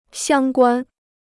相关 (xiāng guān): related; relevant.